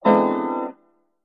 Piano Hit
Category: Sound FX   Right: Commercial